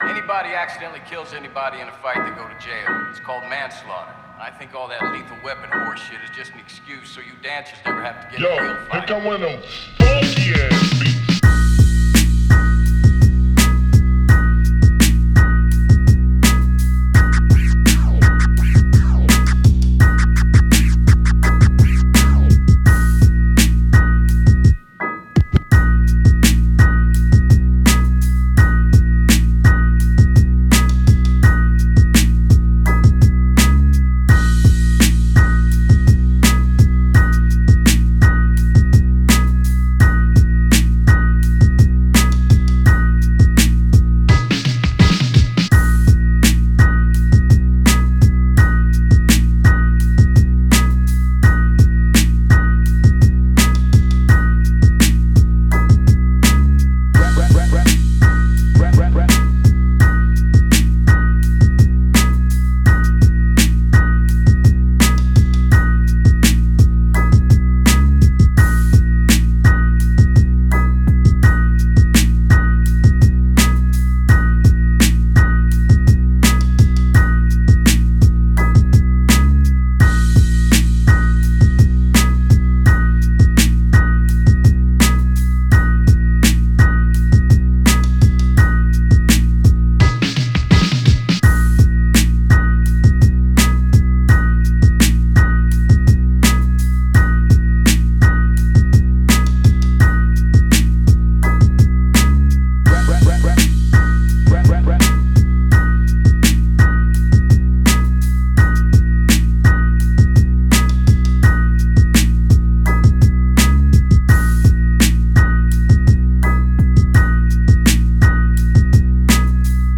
BPM145
NOTAC#m
MOODHard
GÉNERODrill